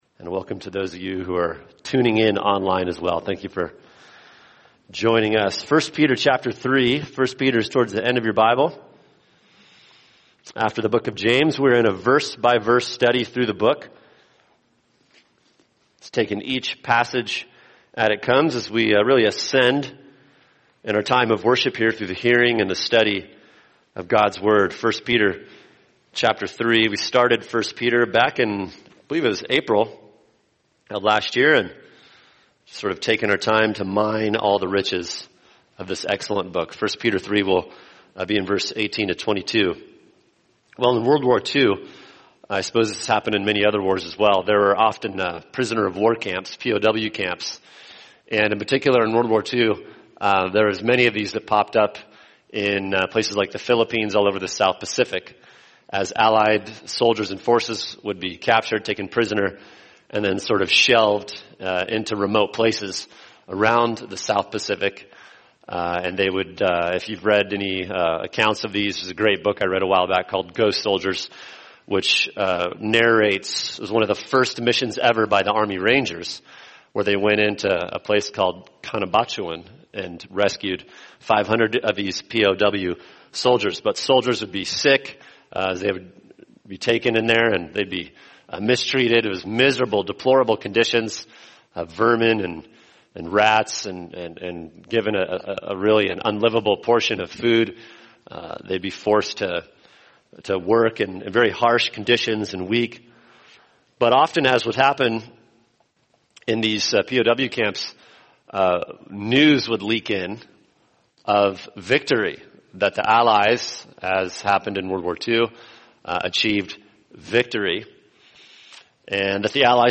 [sermon] 1 Peter 3:18b-22 Why Christians Will Be Victorious | Cornerstone Church - Jackson Hole